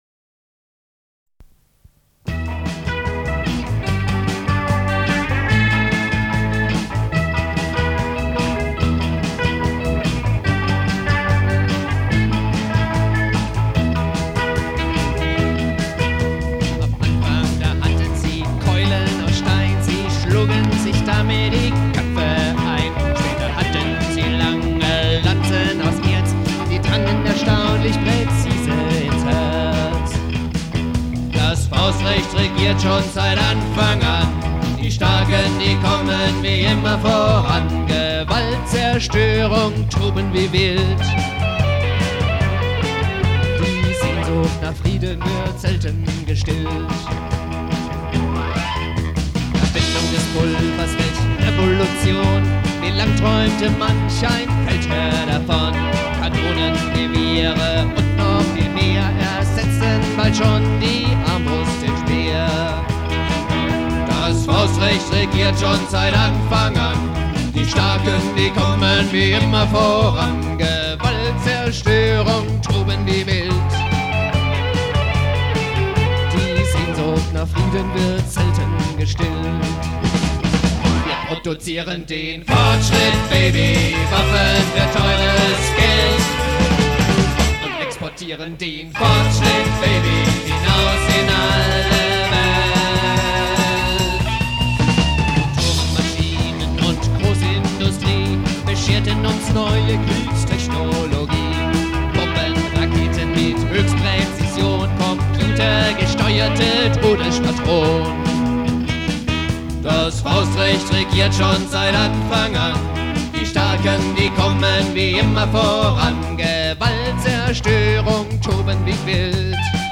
Audio (studio version; 4:30)Herunterladen